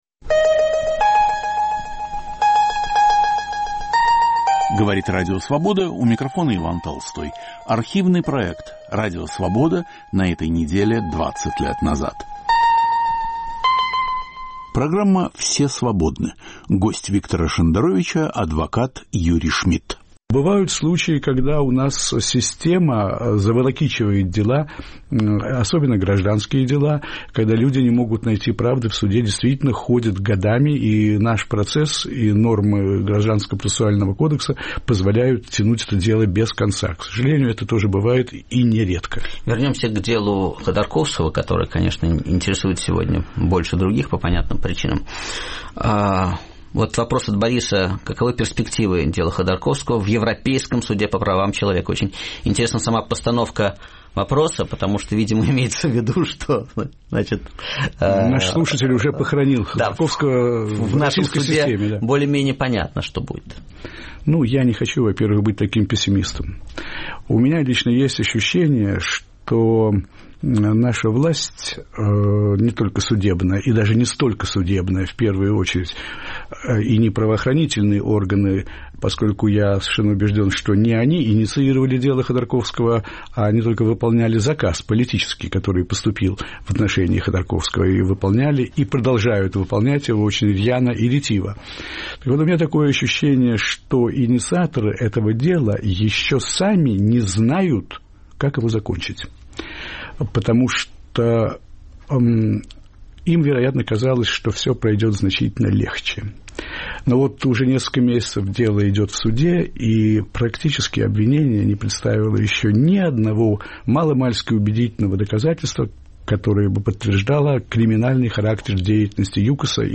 Адвокат, правозащитник о российской судебной практике, о деле Ходорковского. Эфир 3 октября 2004.